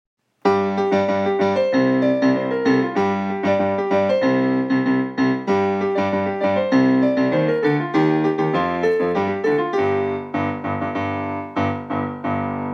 福岡ソフトバンクホークス #43 江川智晃 応援歌